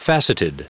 Prononciation du mot facetted en anglais (fichier audio)
Prononciation du mot : facetted